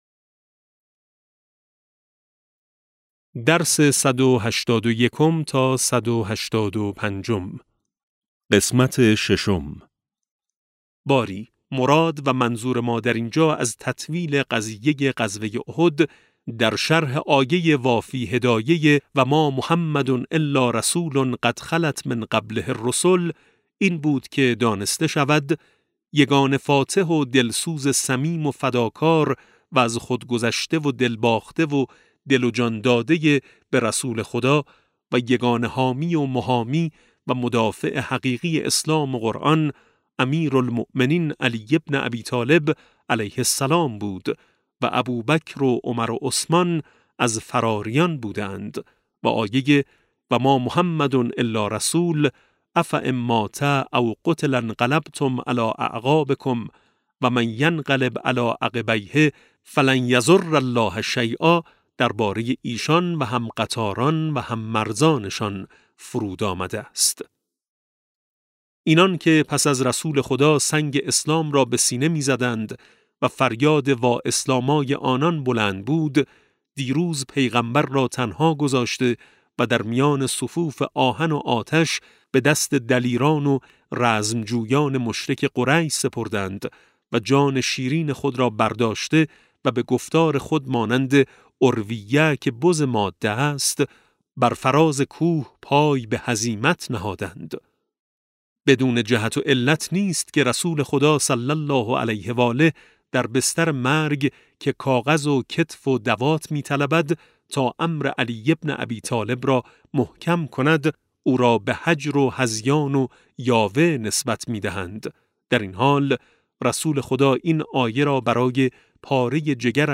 کتاب صوتی امام شناسی ج 13 - جلسه6